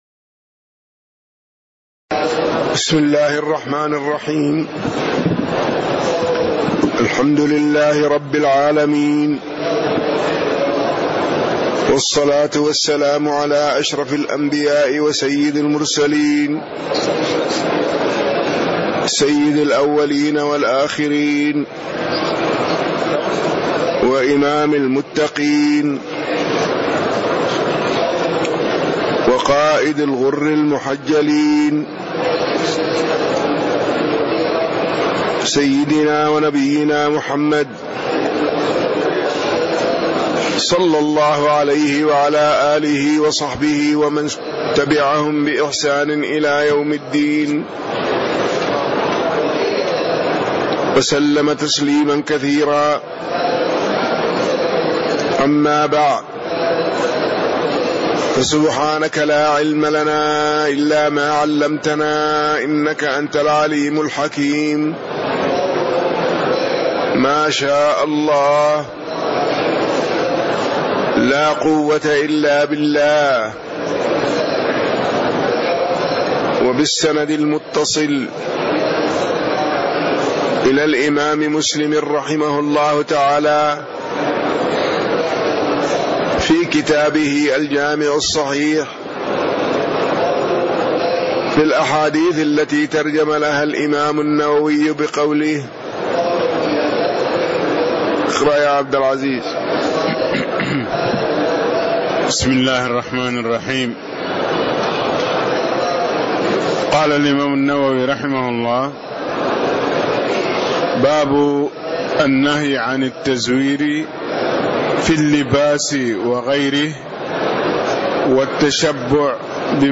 تاريخ النشر ١٠ ذو القعدة ١٤٣٦ هـ المكان: المسجد النبوي الشيخ